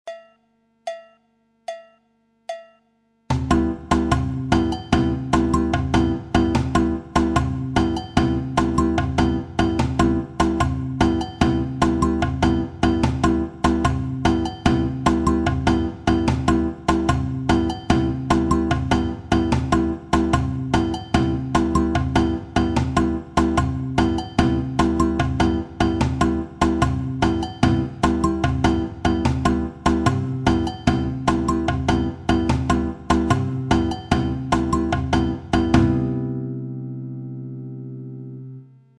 La samba figure 2
batida avec une syncope